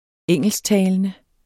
Udtale [ -ˌtæːlənə ]